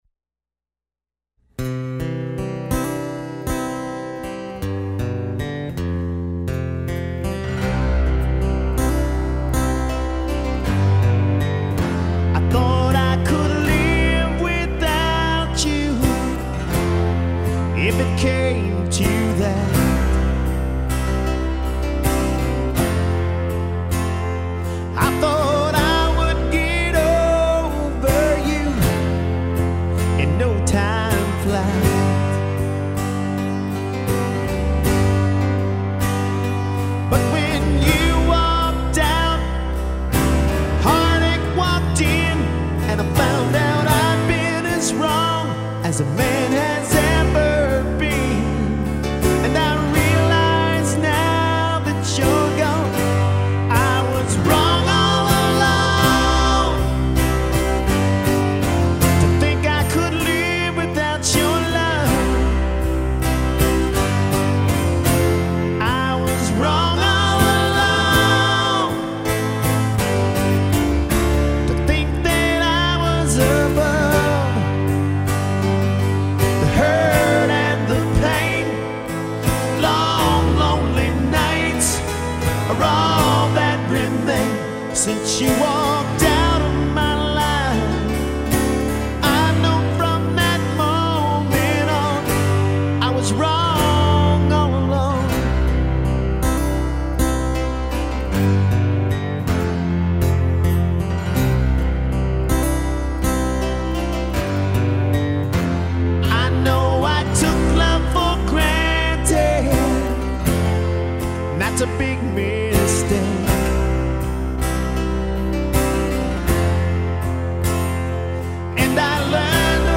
Lead and Background Vocals, Acoustic Guitar
Keyboards, Synth Bass, Drum Programming
Electric Guitar